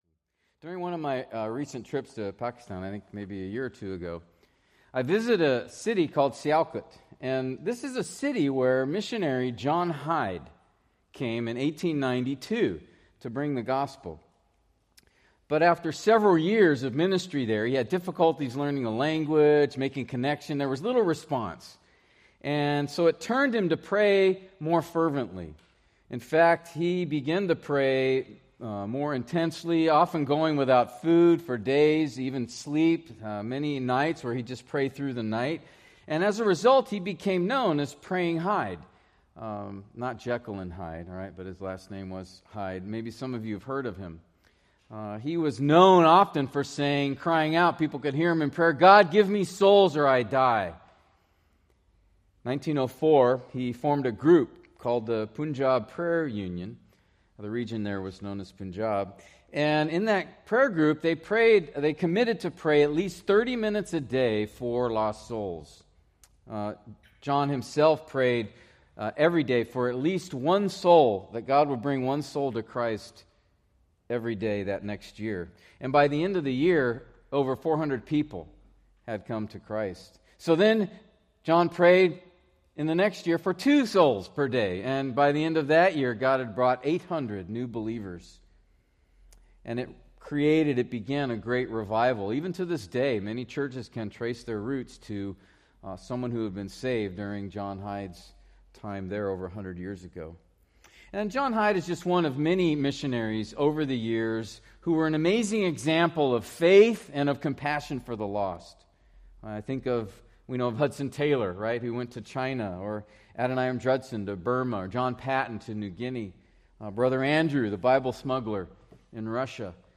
Preached January 11, 2026 from John 4:1-42